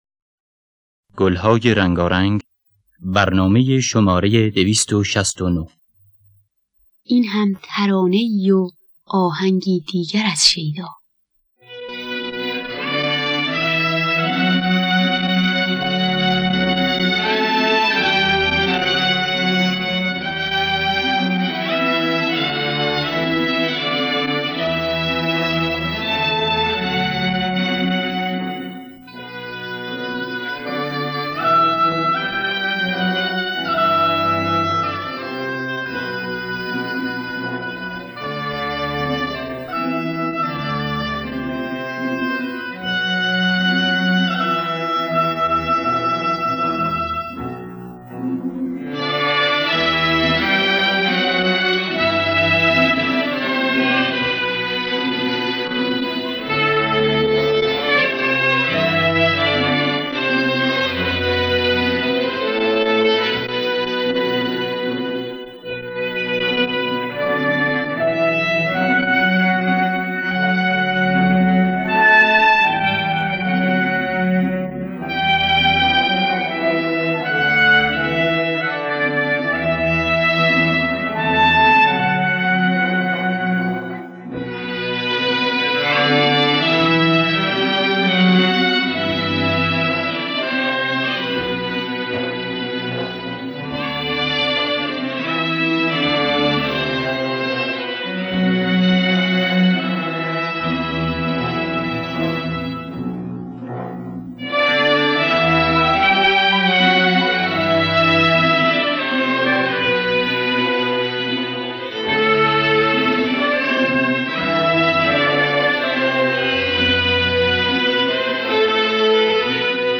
در دستگاه دشتی